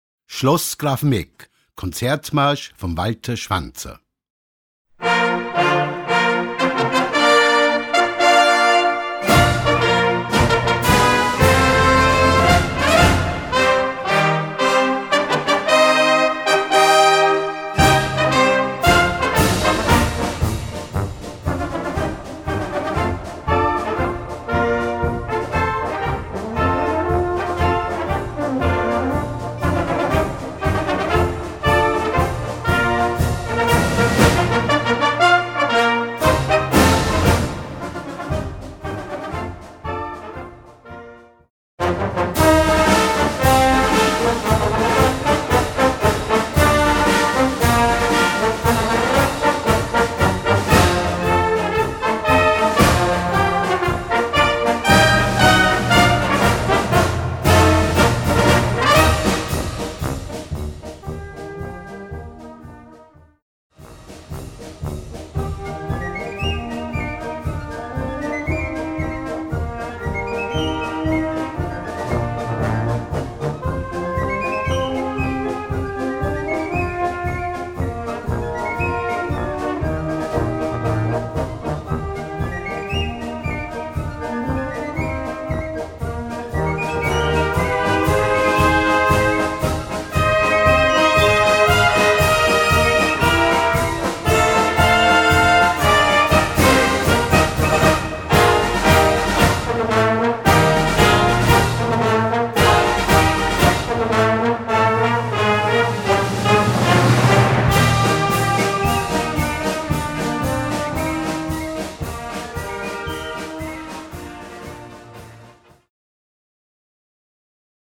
Gattung: Konzertmarsch
Besetzung: Blasorchester
Mit einem Piccolo-Solo im Trio.